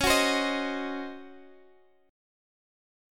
C#M7sus2sus4 chord